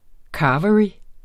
Udtale [ ˈkɑːvəɹi ]